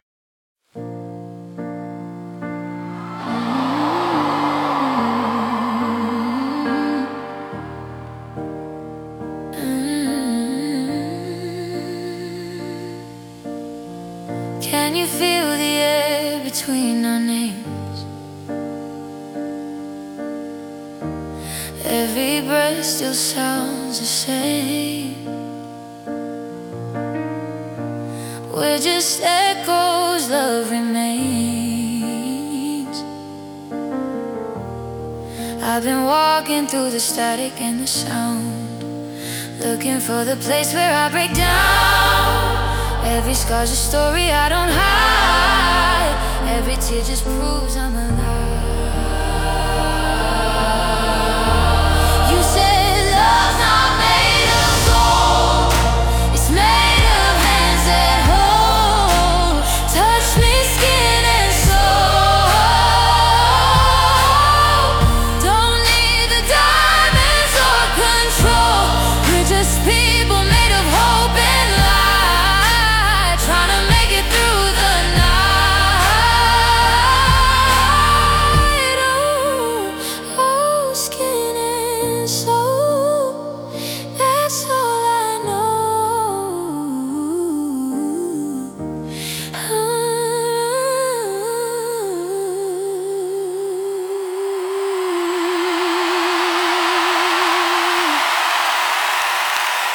A cinematic blend of gospel and R&B.